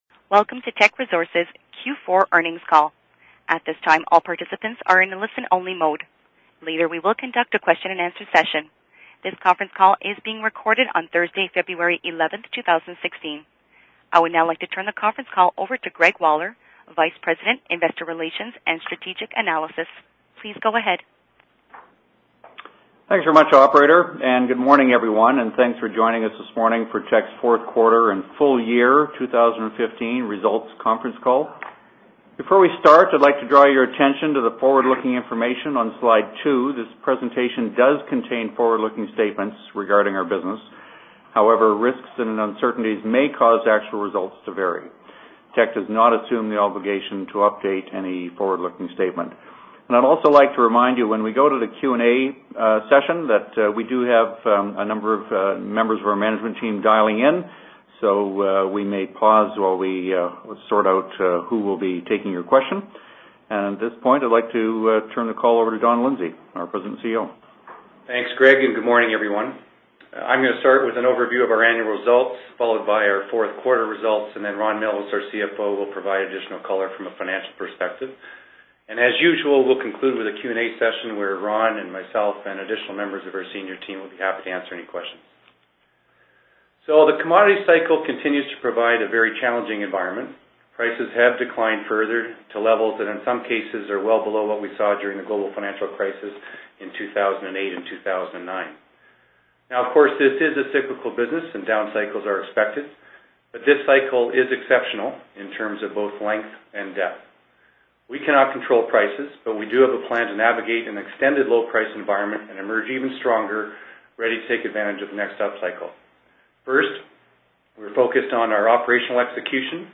Q4 Financial Report [PDF - 0.22 MB] Q4 2015 Financial Report Presentation Slides [PDF - 1.66 MB] Q4 2015 Financial Report Conference Call - Audio [MP3 - 27.12 MB] Q4 2015 Financial Report Conference Call Transcript [PDF - 0.30 MB]